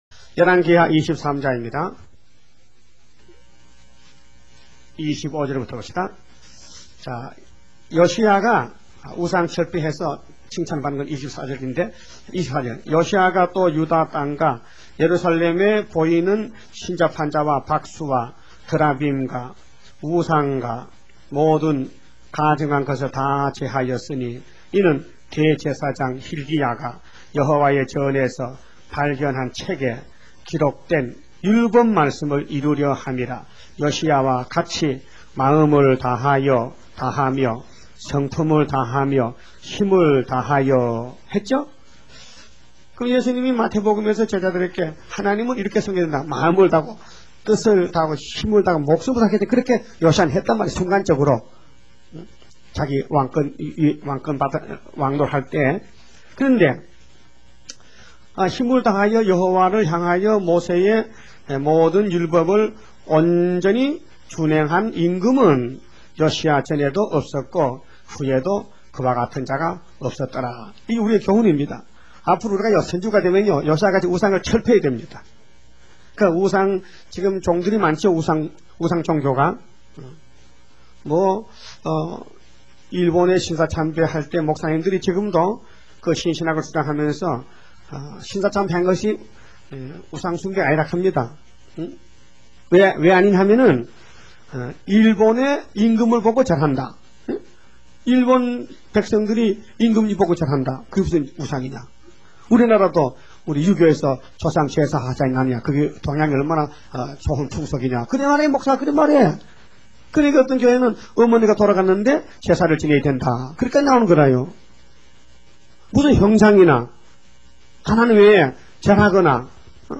음성 설교